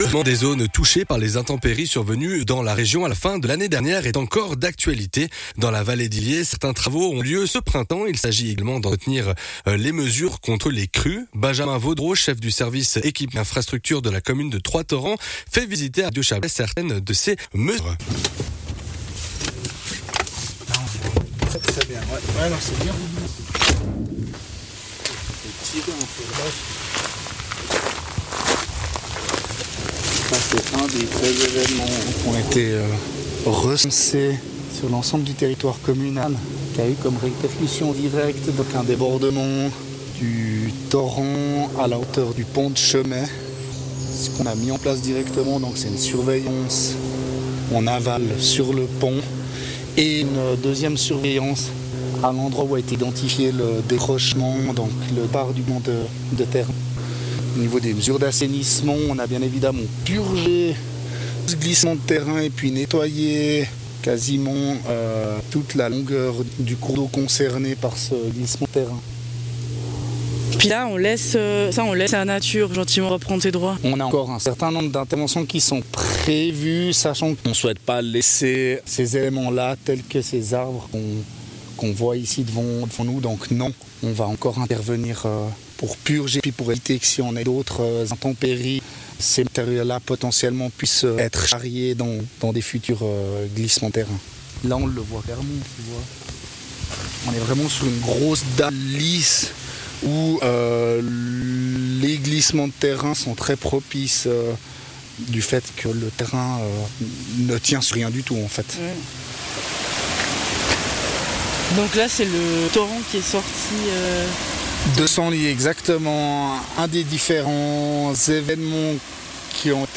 Reportage au coeur de Val d'Illiez suite aux intempéries de décembre